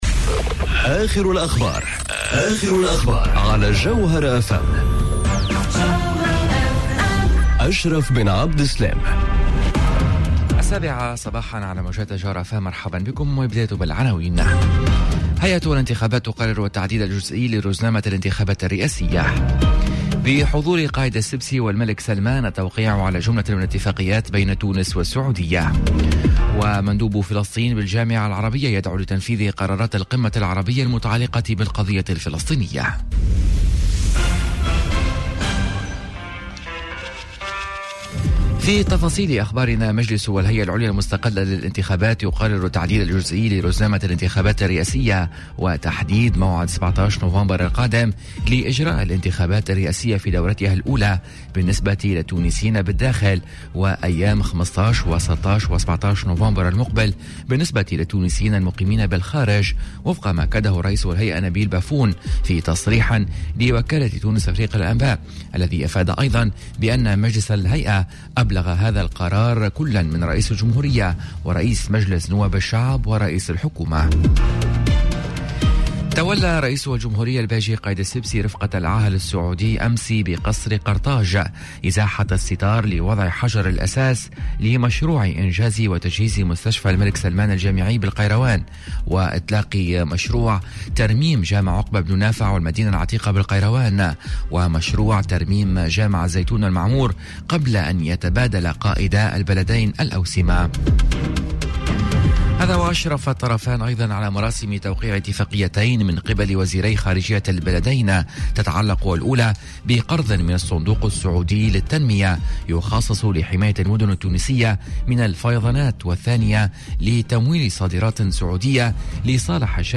نشرة أخبار السابعة صباحا ليوم السبت 30 مارس 2019